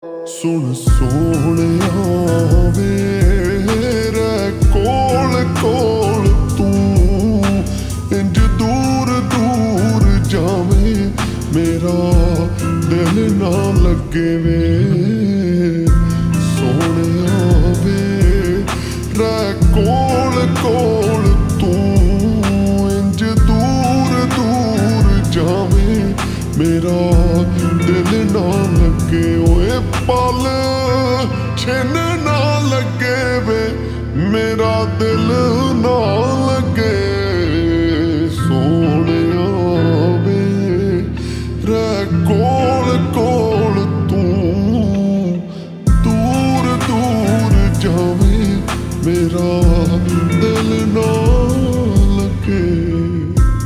Punjabi Songs
(Slowed + Reverb)